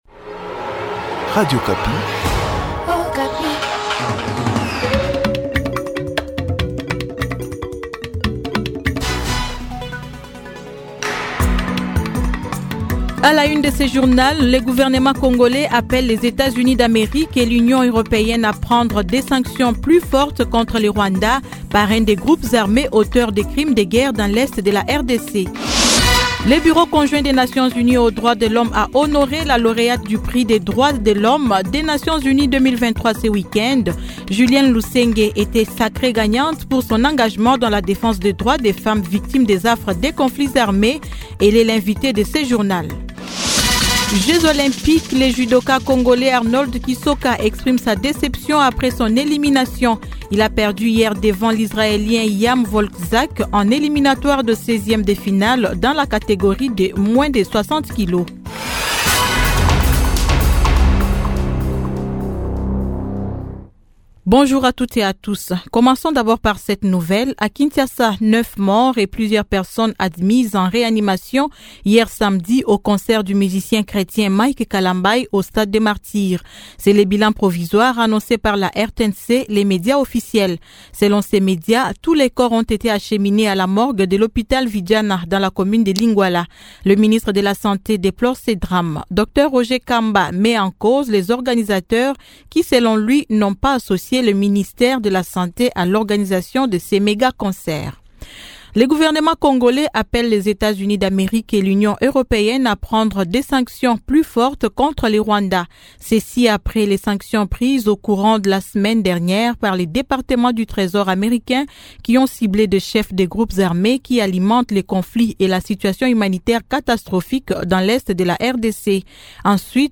Journal matin 07H-08H